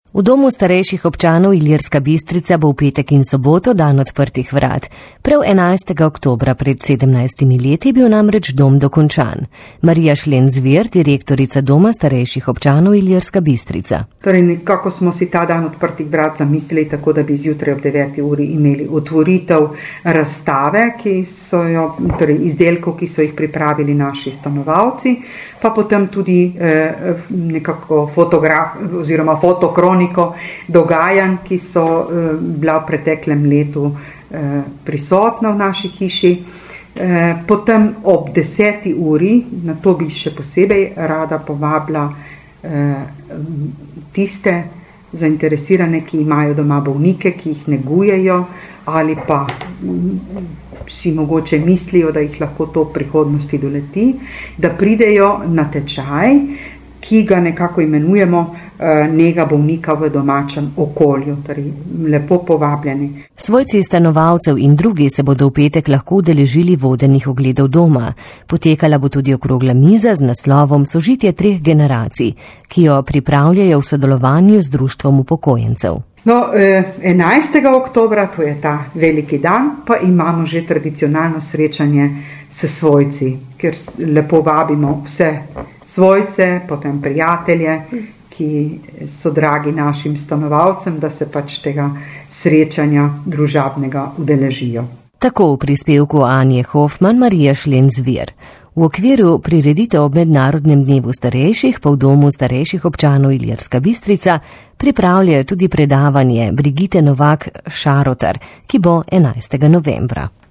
V Domu starejših občanov Ilirska Bistrica ob dnevu odprtih vrat pripravljajo tudi vodene oglede doma, okroglo mizo z naslovom Sožitje treh generacij in prijetno čajanko. Več  v pogovoru